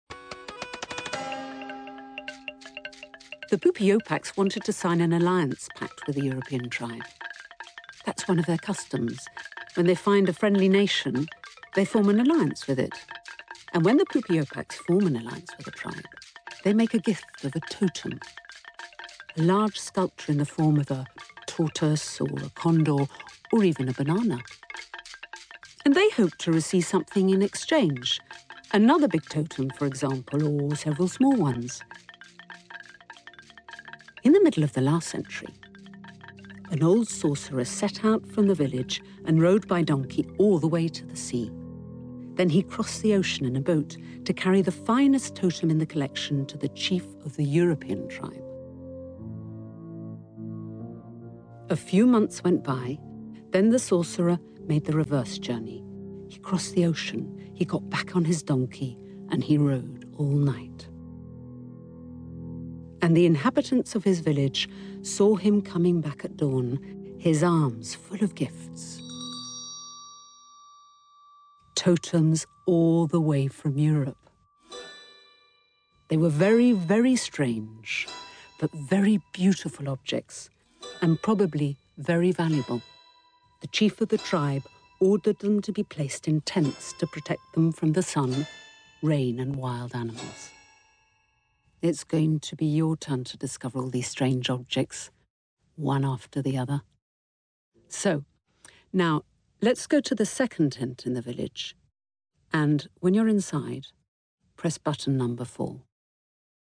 Bandes-son
Comédienne